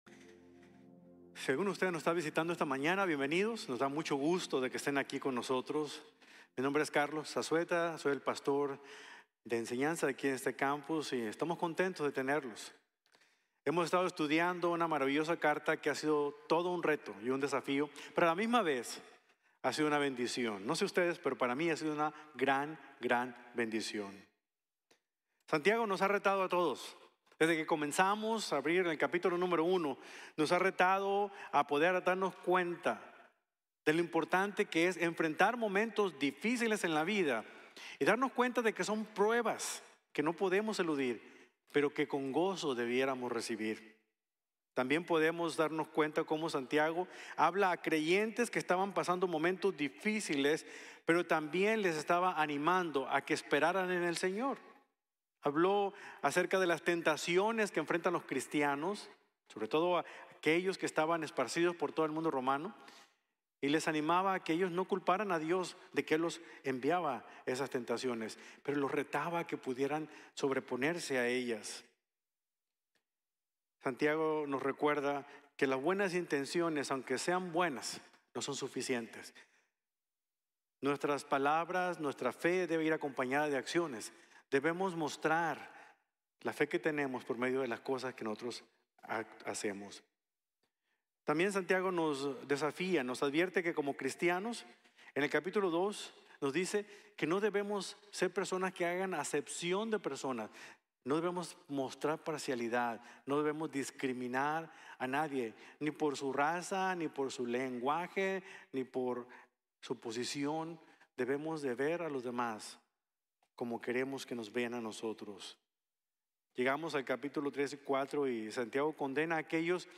Arrollidarse con Poder | Sermón | Iglesia Bíblica de la Gracia